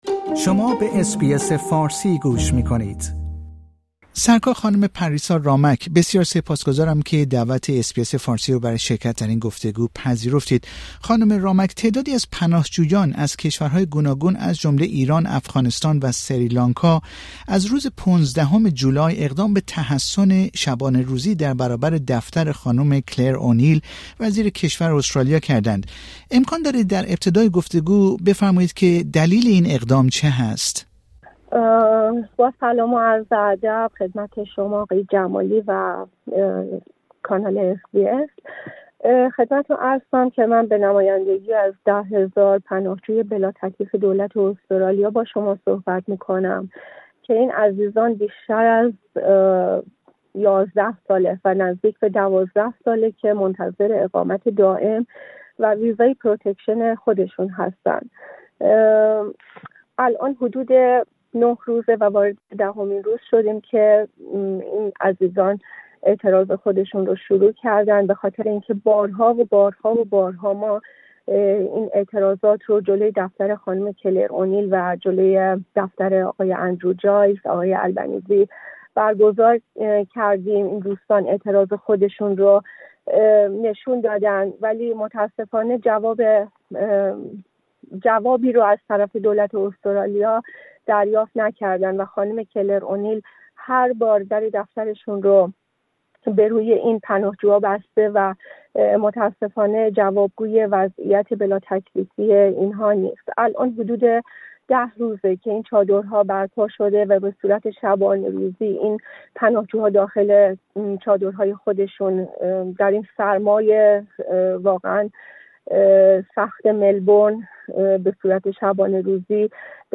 در گفتگو با رادیو اس بی اس فارسی درباره این تحصن سخن می گوید.